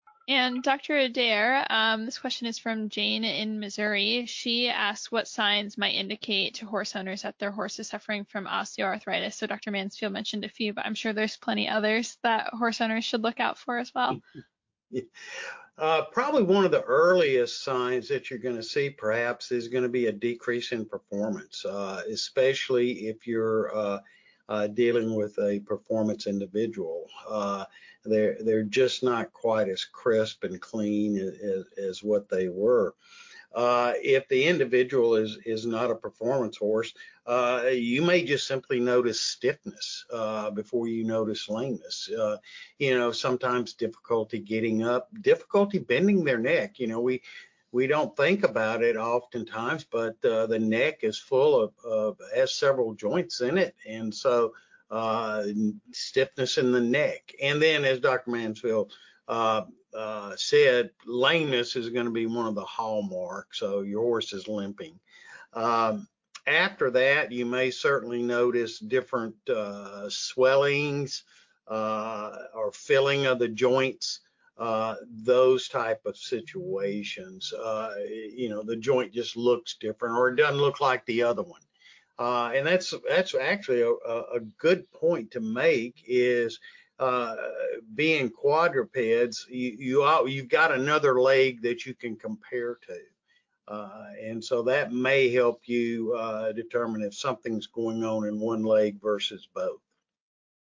This podcast is an excerpt of our Ask TheHorse Live Q&A, "Equine Joint Care Therapies."